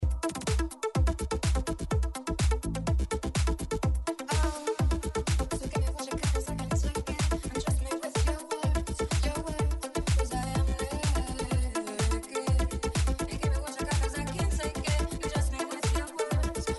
The vocalist is very familiar!